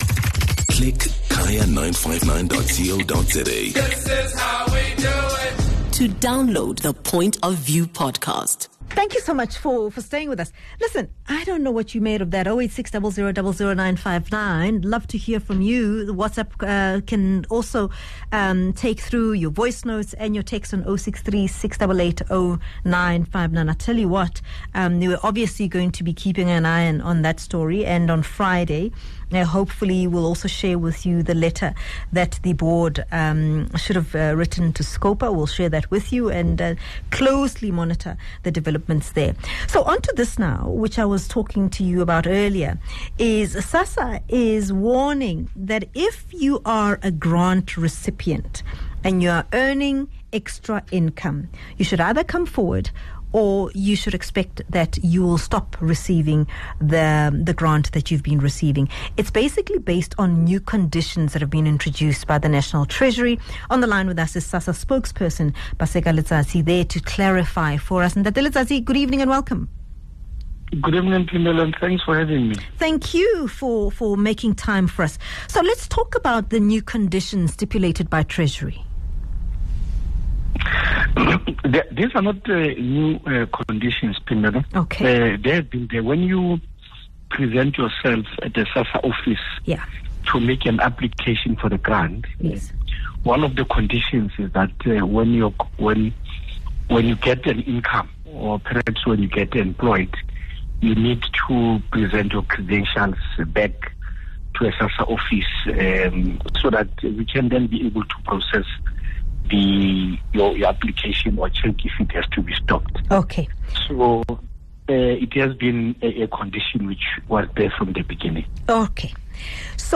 MUT Radio